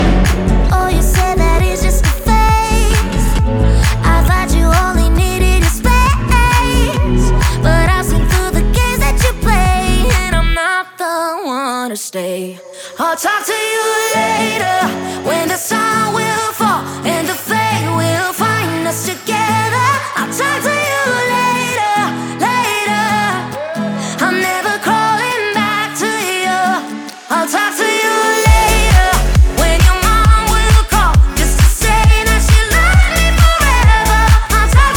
2025-07-04 Жанр: Танцевальные Длительность